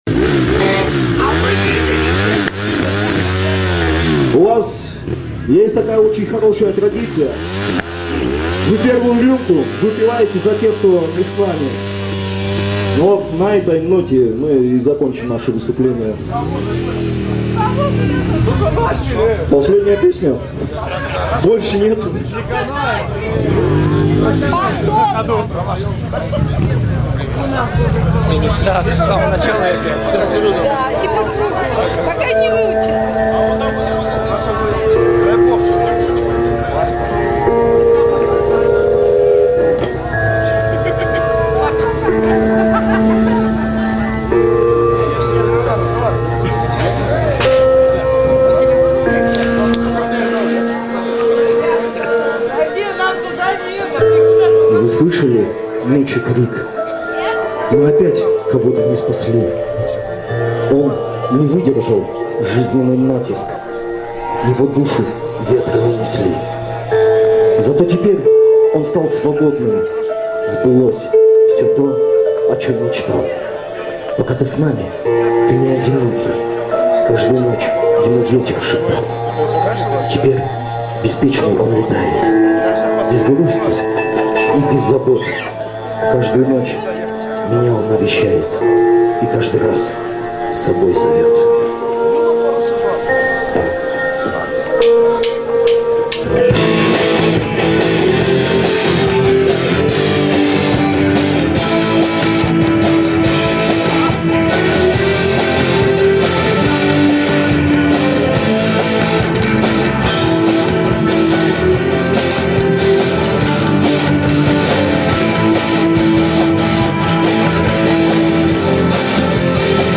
(Live)